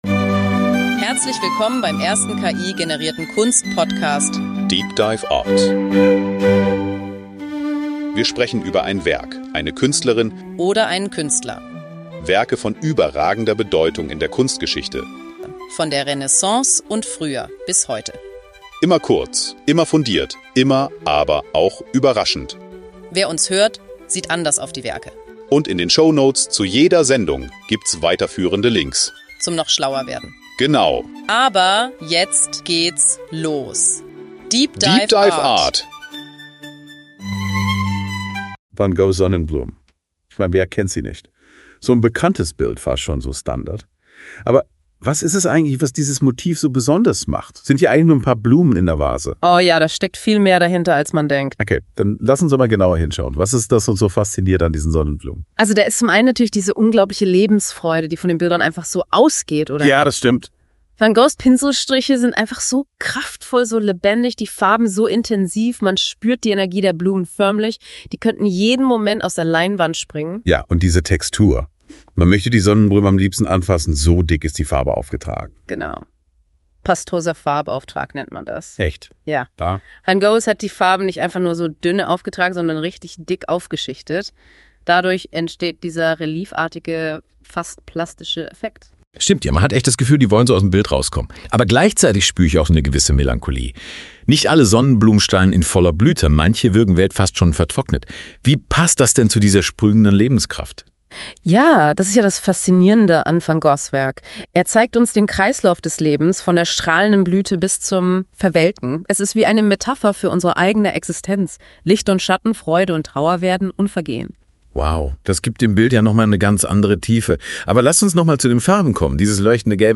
In dieser tiefgehenden Diskussion über Van Goghs Sonnenblumen werden die verschiedenen Facetten des berühmten Kunstwerks beleuchtet.